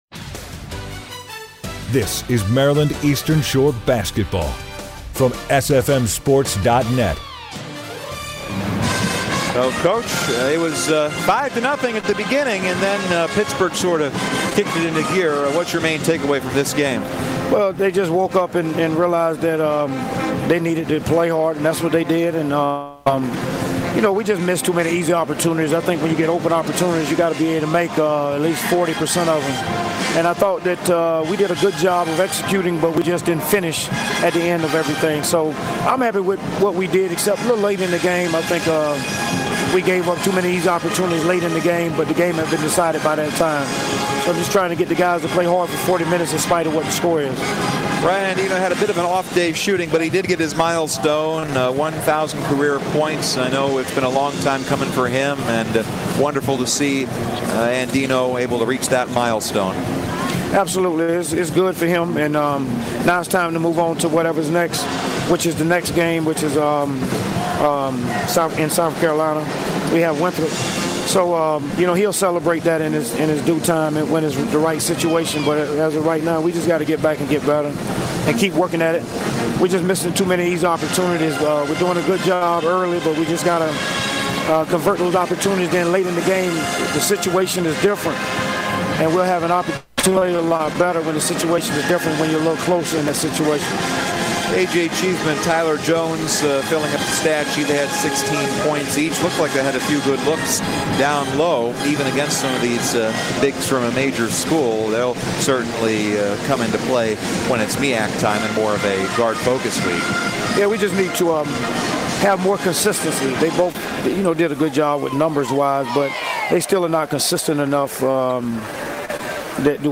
Post Game Interview